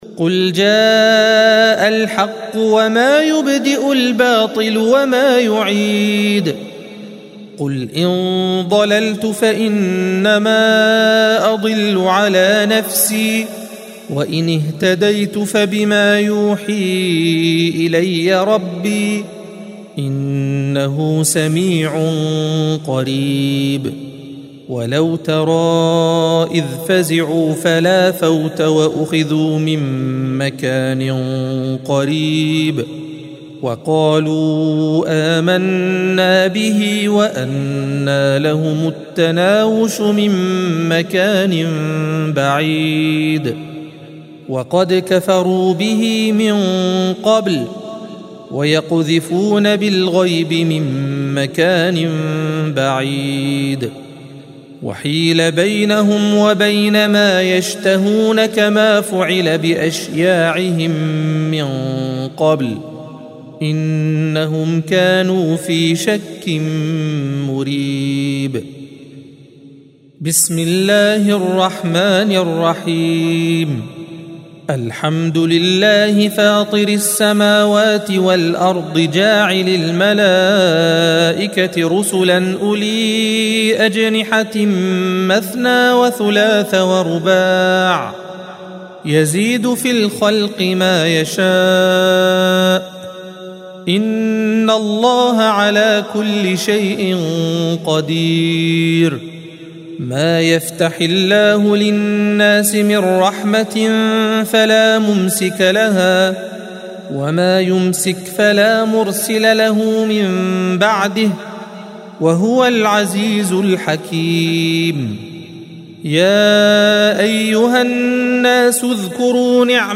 الصفحة 434 - القارئ